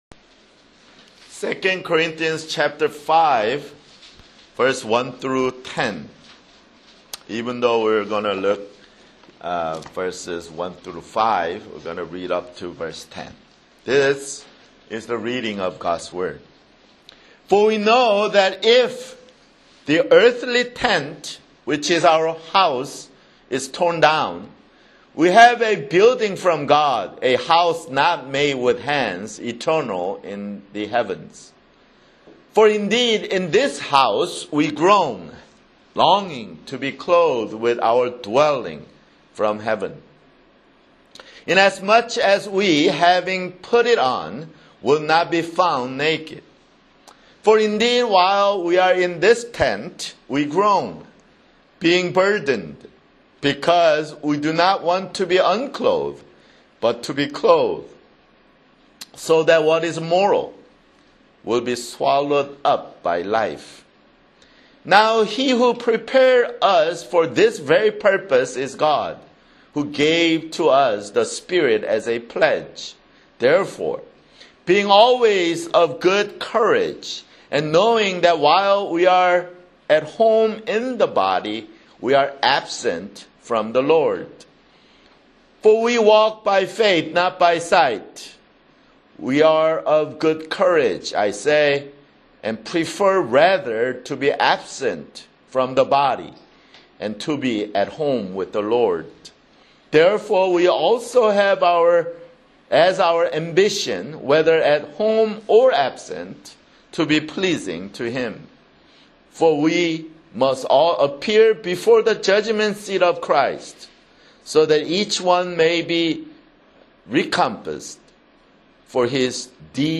[Sermon] 2 Corinthians (25)